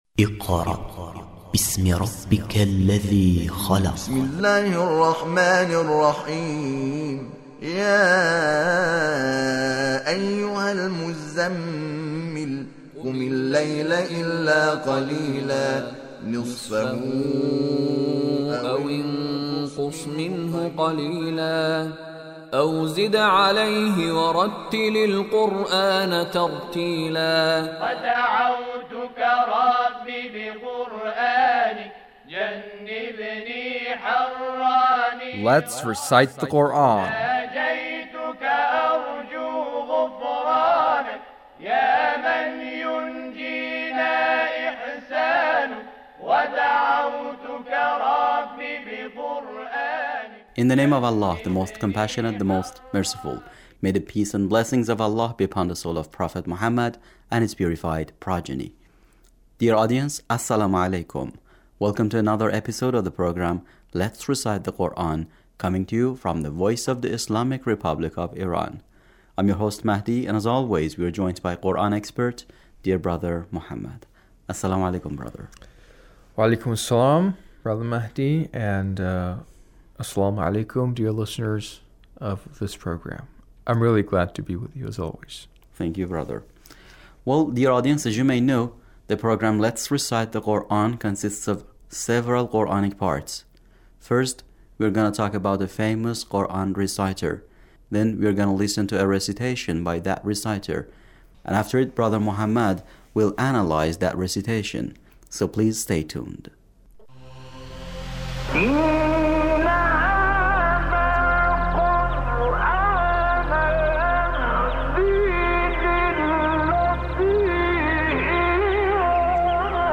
Mostafa Esmaeil recitation